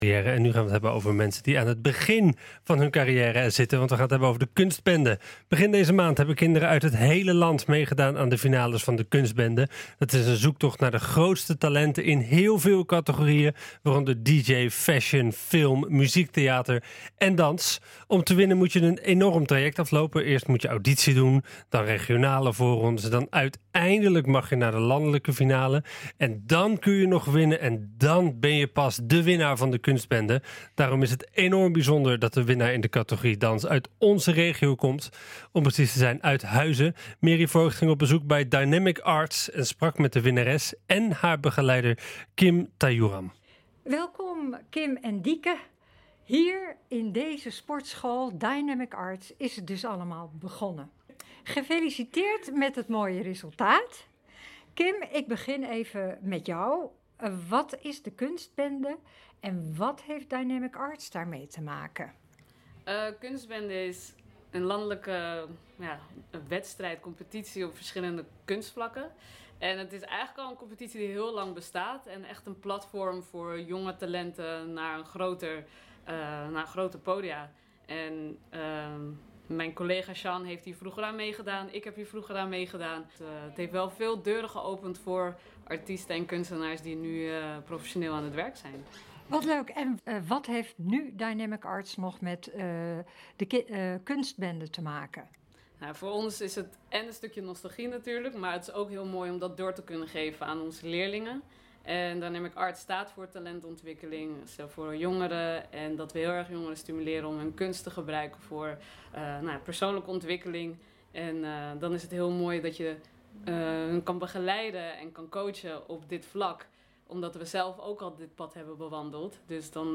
NH Gooi Zaterdag - In gesprek met de winnaar van Kuntsbende, Dynamic Arts